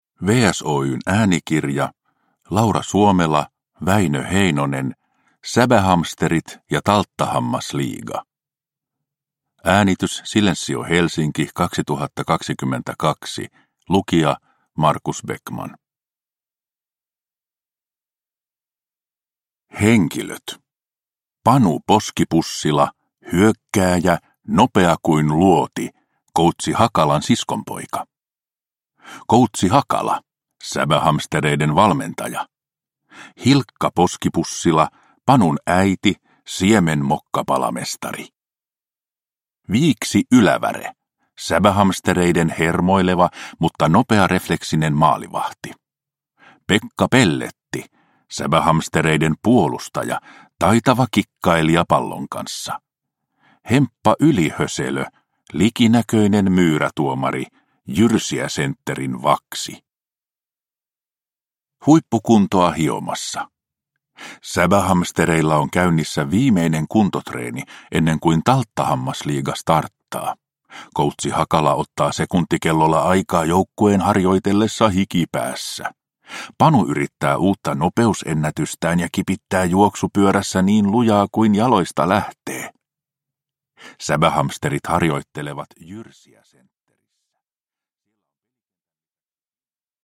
Säbähamsterit ja Talttahammasliiga – Ljudbok – Laddas ner